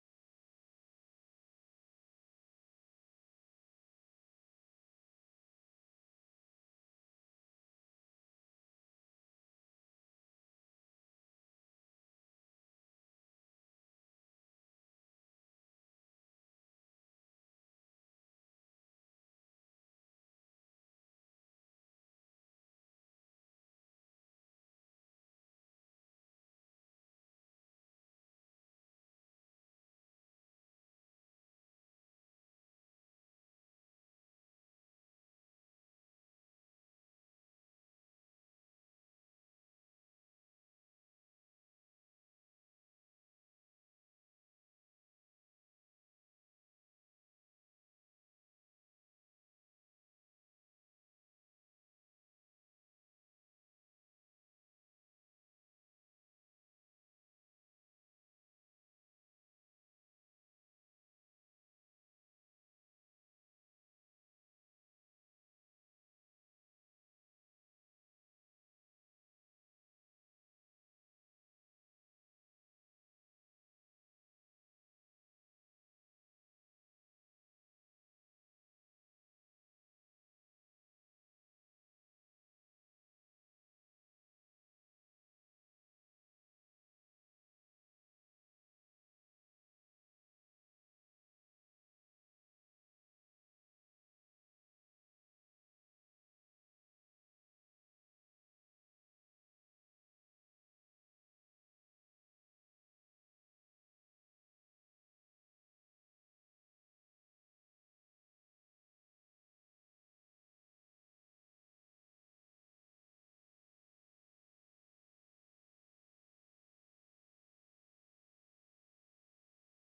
broadcast live from Kitty's Restaurant and Market Café at 60 S. Front Street in Hudson